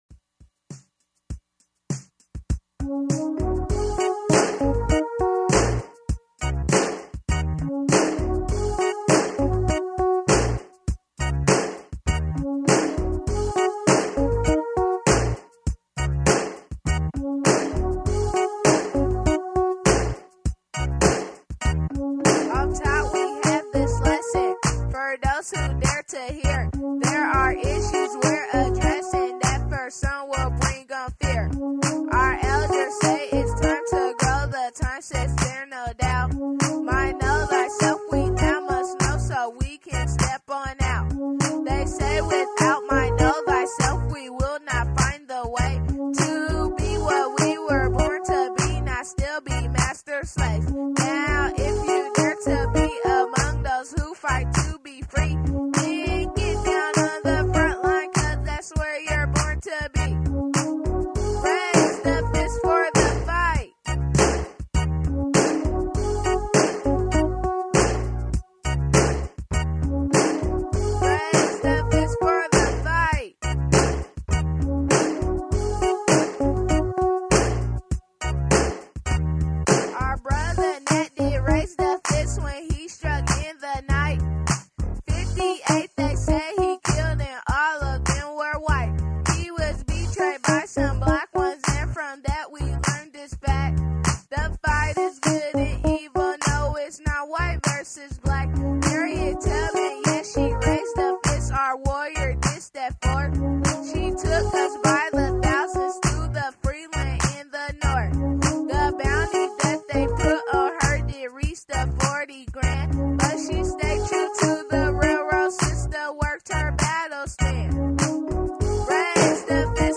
Armed solely with a simple keyboard